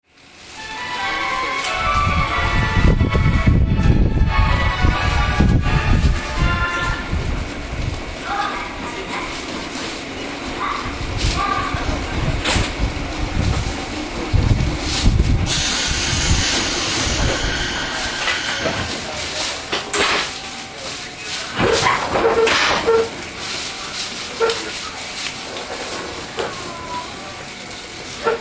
走行音
TK03 211系 熱海→湯河原 5:29 8/10 --